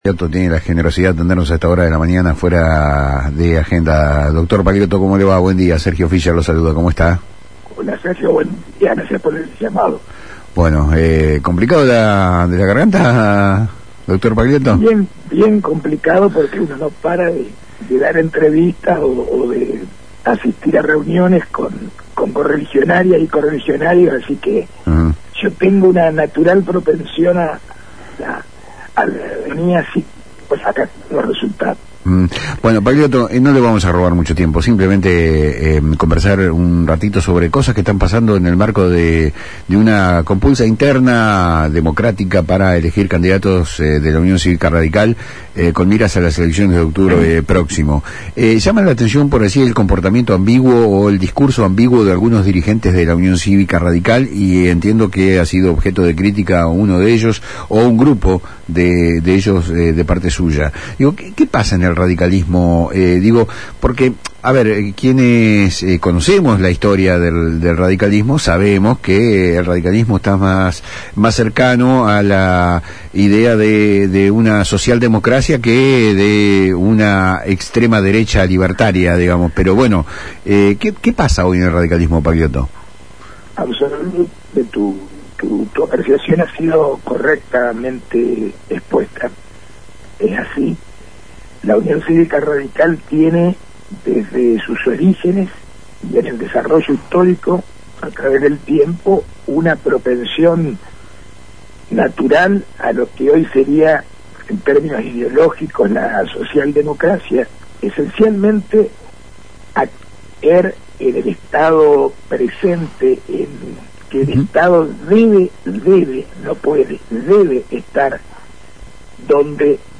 En una entrevista